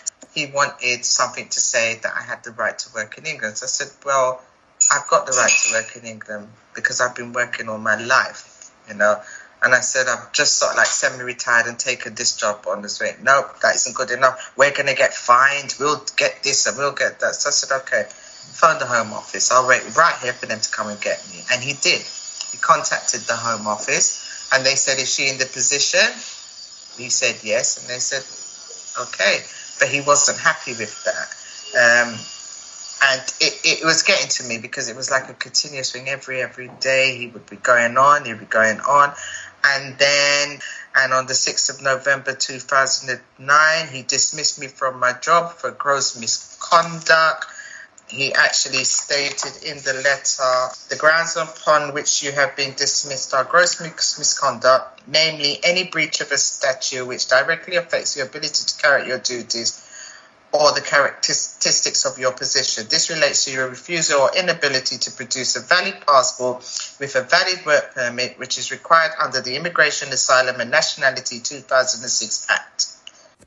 interviewee
This oral history excerpt has been drawn from the scoping project ‘Nationality, Identity and Belonging: An Oral History of the ‘Windrush Generation’ and their Relationship to the British State, 1948-2018'.